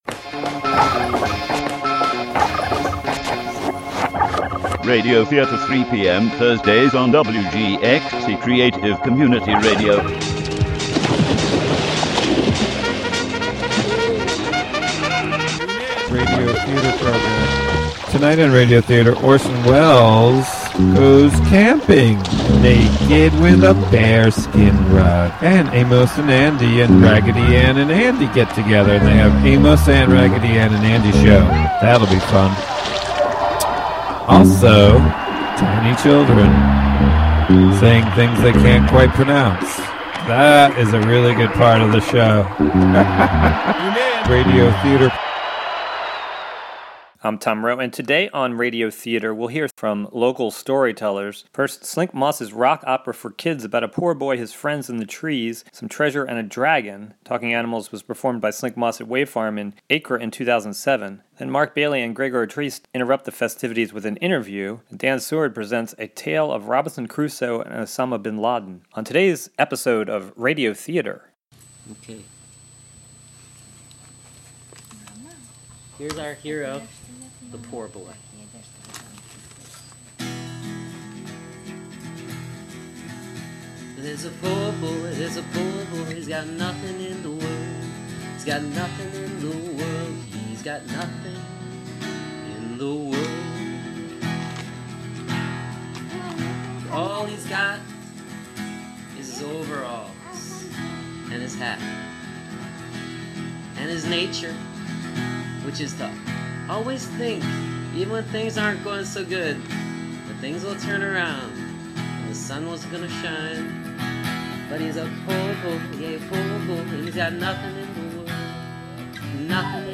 Original radio theatre